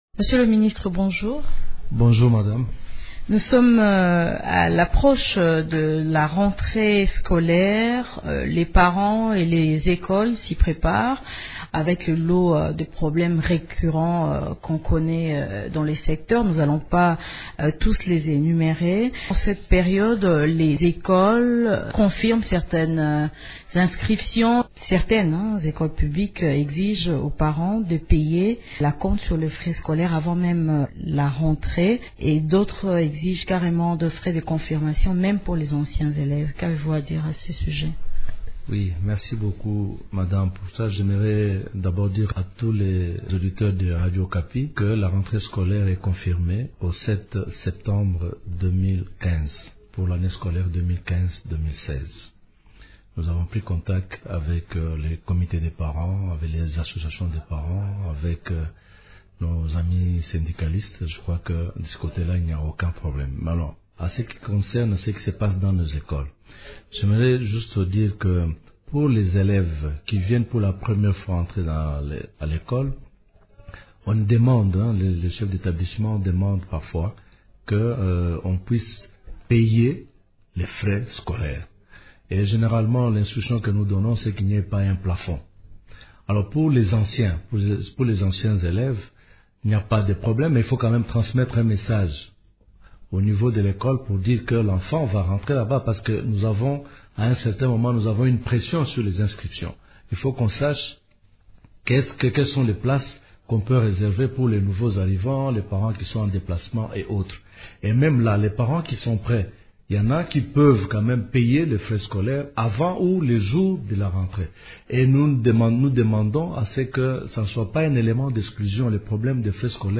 Invité de Radio Okapi ce matin, le ministre de l’Enseignement primaire, secondaire et et Initiation à la nouvelle citoyenneté, Maker Mwangu Famba, a interdit aux chefs d’établissements scolaires d’exiger des frais de confirmation aux parents d’élevés.